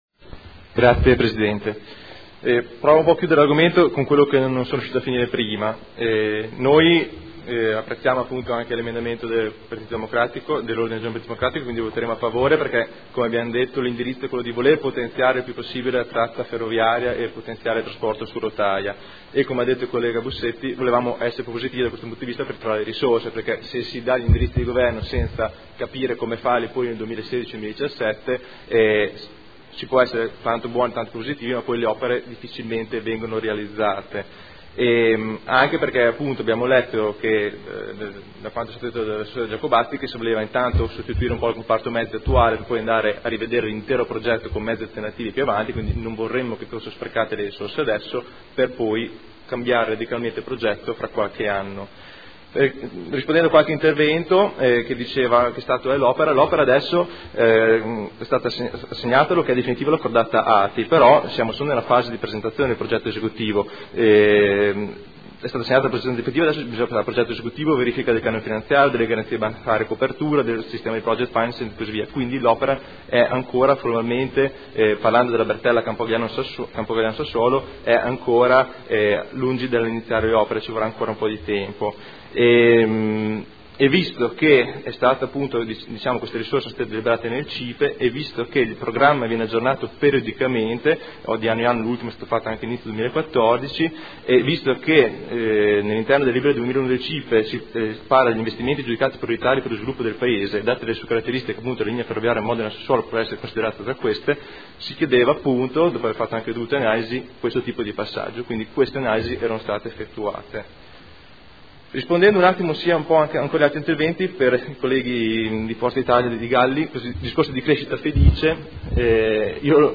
Marco Rabboni — Sito Audio Consiglio Comunale